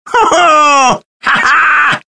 snip ability shrapnel 03 sound effects